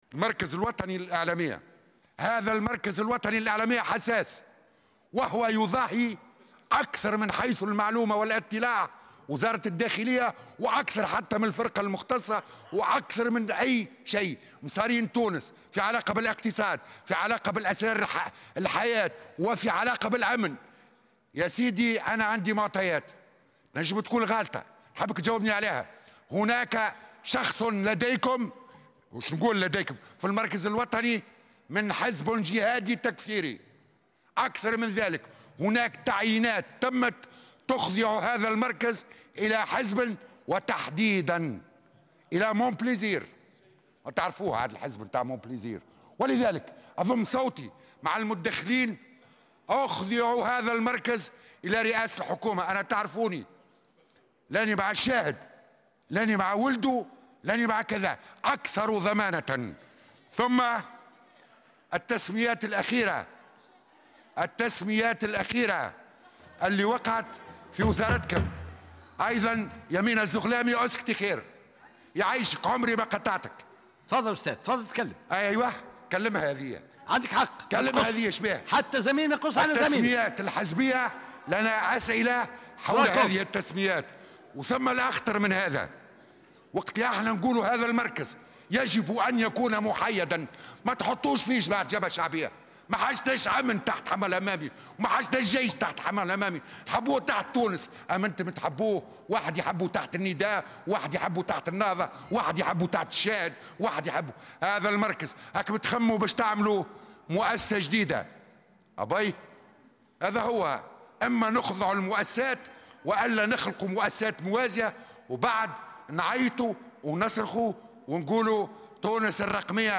حذر النائب عن الجبهة الشعبية عمّار عمروسية خلال مداخلة له في جلسة عامة...
حذر النائب عن الجبهة الشعبية "عمّار عمروسية" خلال مداخلة له في جلسة عامة بالبرلمان عقدت اليوم الثلاثاء 10 جويلية 2018 حول الوضع العام بالبلاد والنظر في عدد من مشاريع القوانين بحضور عدد من الوزراء، من سيطرة بعض الأحزاب على المركز الوطني للإعلامية.